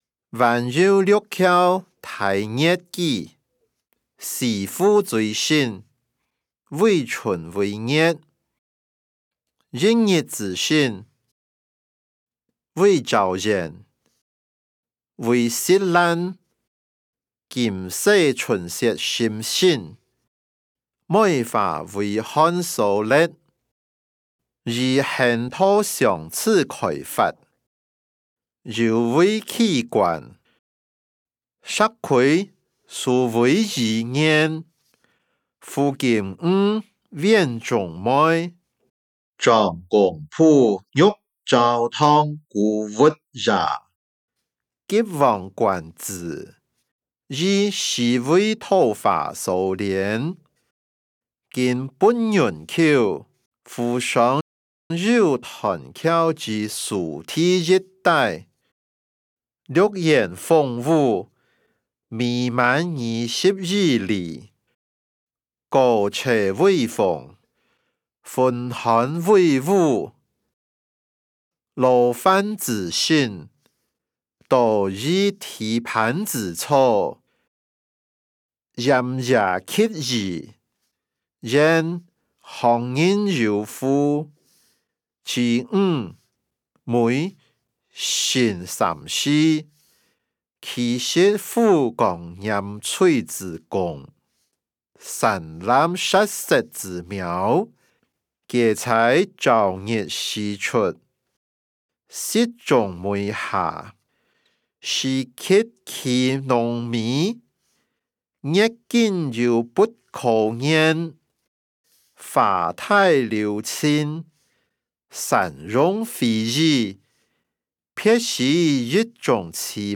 歷代散文-晚遊六橋待月記音檔(饒平腔)